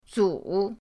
zu3.mp3